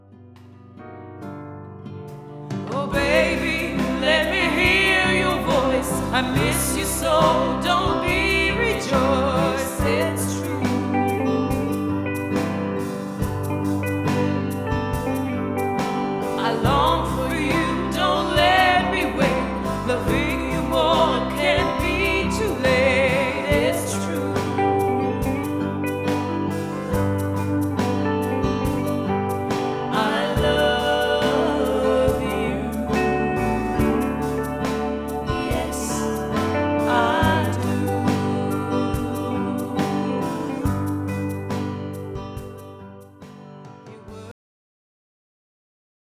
Guitares: Électrique / Acoustique
Piano / Orgue